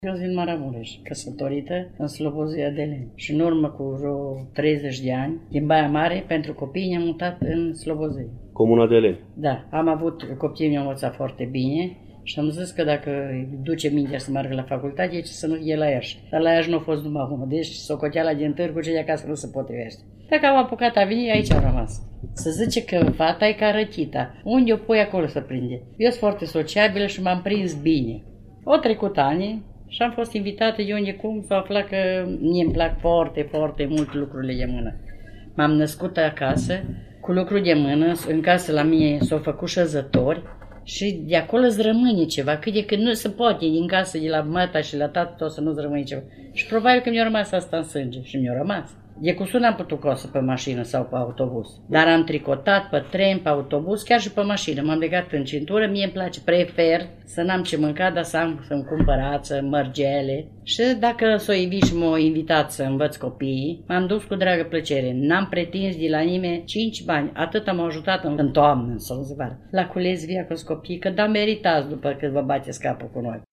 I-am întâlnit, zilele trecute, cu emoție, bucurie și energie pozitivă, în incinta Bibliotecii Județene “Gh. Asachi” Iași, unde au venit să ne prezinte, o mică parte din valoarea tradiților și bogăției lor artistice, reprezentative pentru vatra etnofolclorică Deleni (Hârlău), Iași.